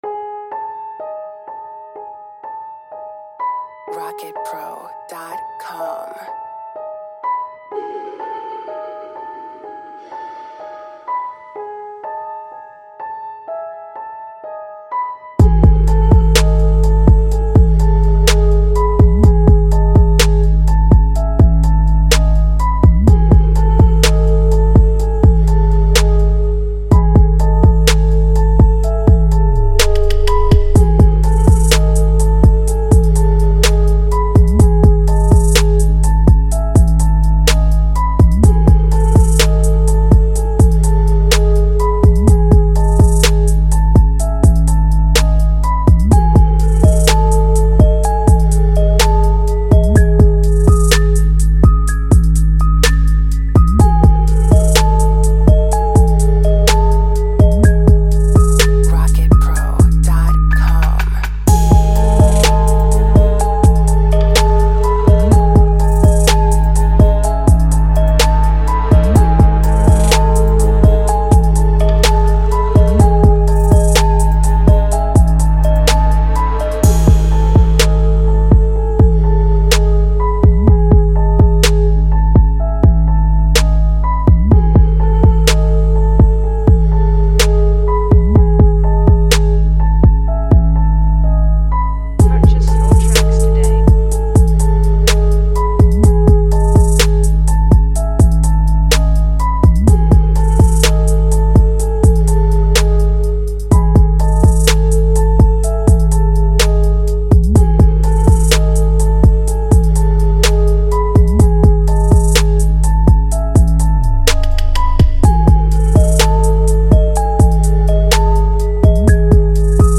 Dark trap beat with bells, synths, and distorted 808s.
78.3 BPM.